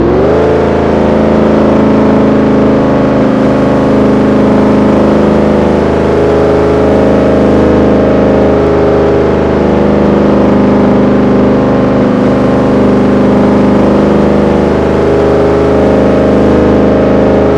hsvgts_revdown.wav